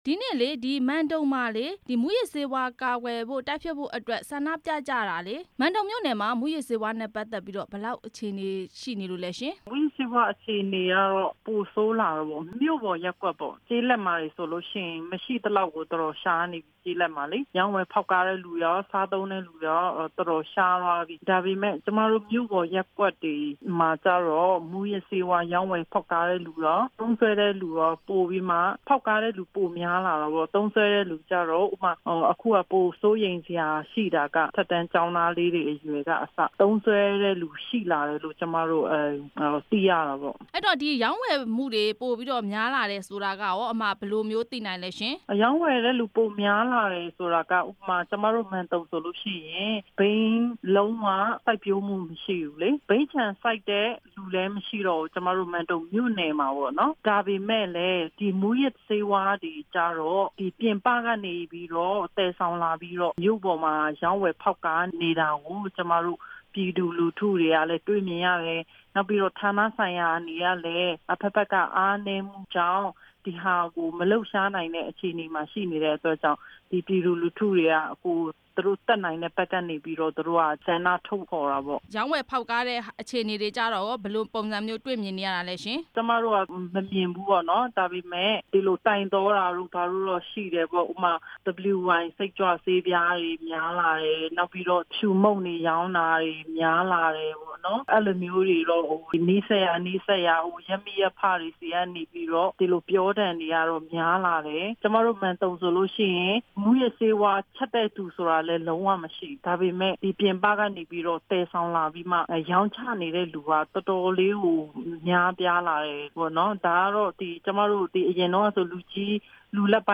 မန်တုံမြို့ မူးယစ်ဆေးဝါးဆန့်ကျင်ရေးဆန္ဒပြပွဲ မေးမြန်းချက်